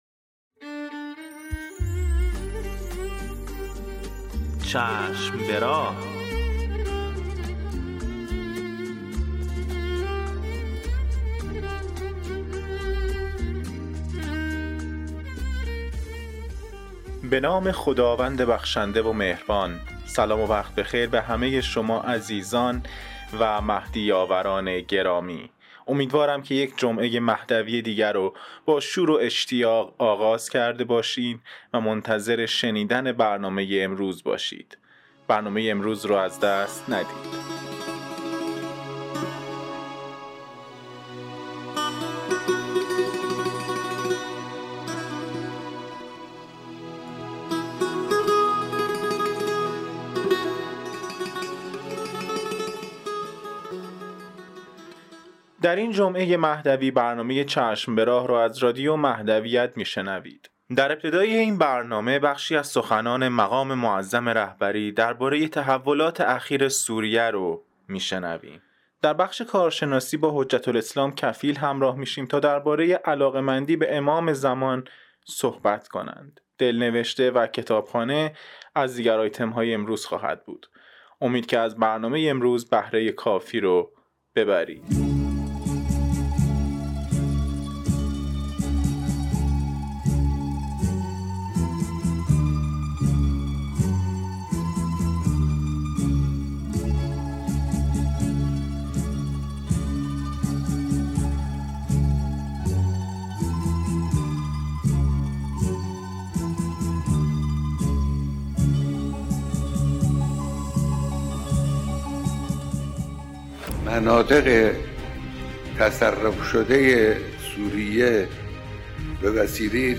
قسمت صد و پنجاه و هشتم مجله رادیویی چشم به راه که با همت روابط عمومی بنیاد فرهنگی حضرت مهدی موعود(عج) تهیه و تولید شده است، منتشر شد.